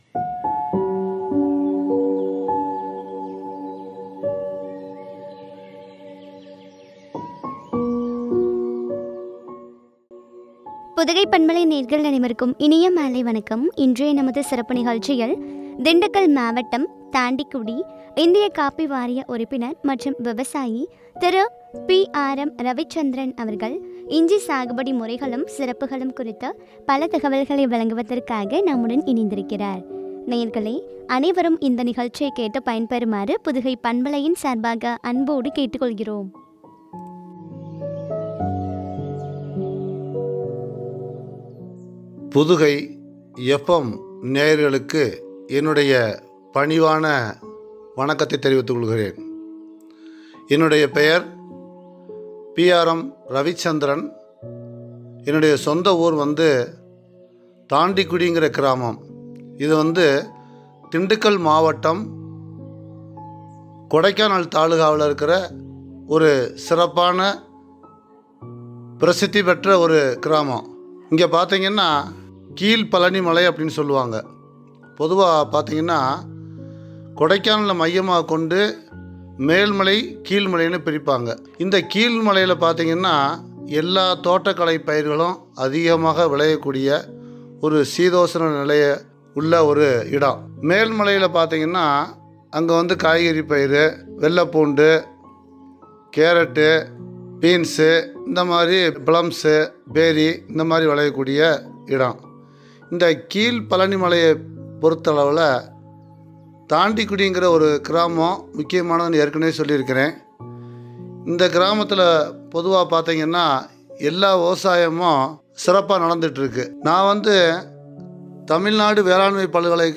இஞ்சி சாகுபடி முறைகளும், சிறப்புகளும் பற்றிய உரையாடல்.